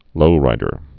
(lōrīdər)